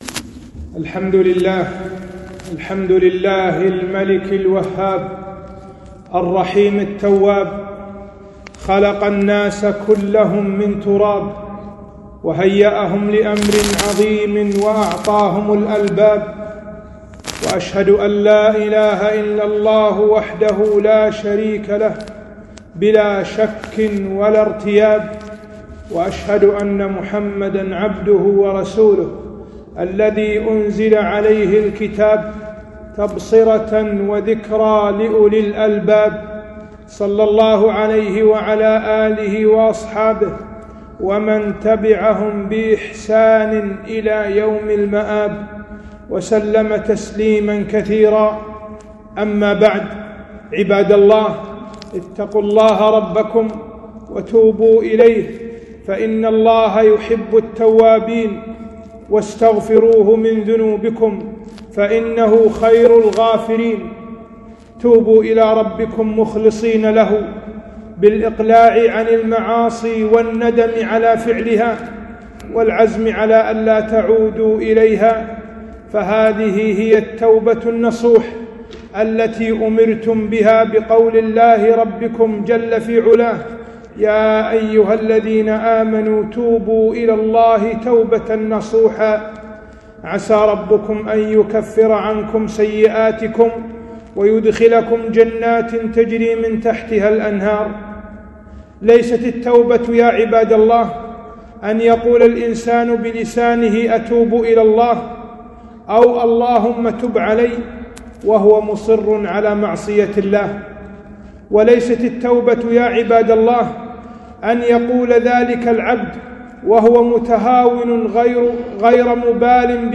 خطبة - حقيقة التوبة